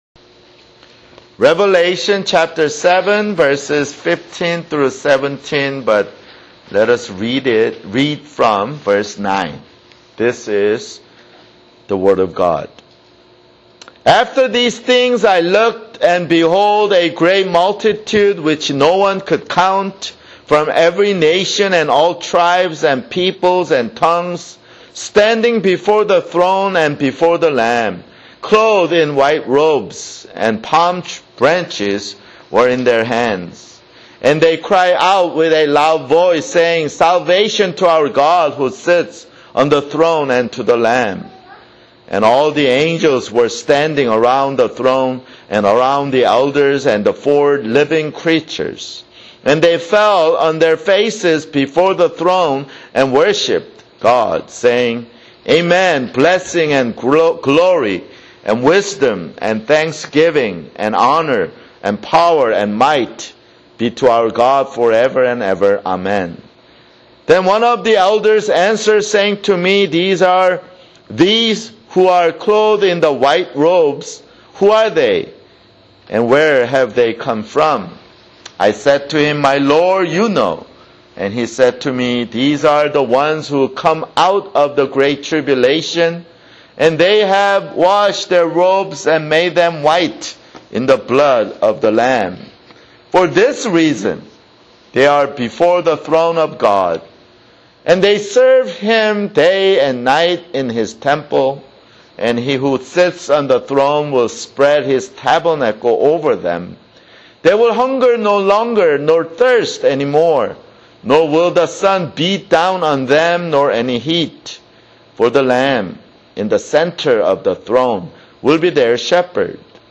[Sermon] Revelation (30)